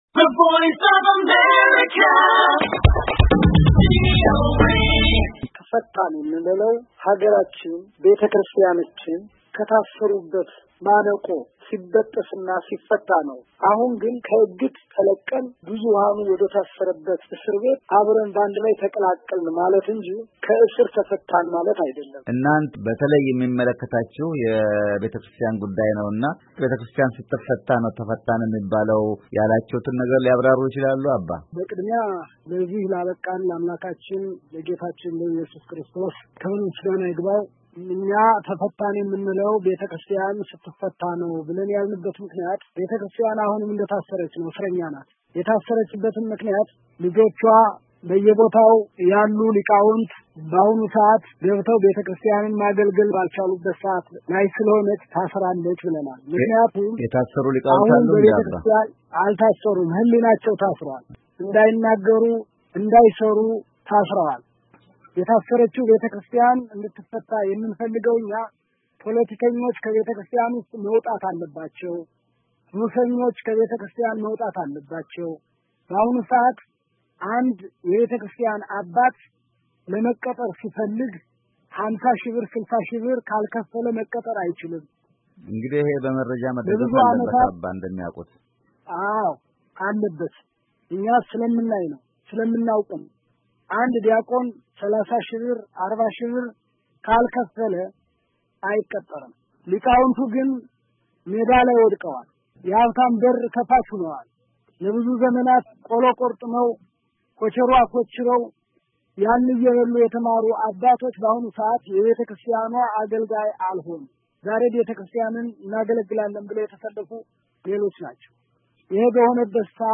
ከእስር የተፈቱት የዋልድባ መነኮሳት ከቪኦኤ ጋር ያደረጉት ቆይታ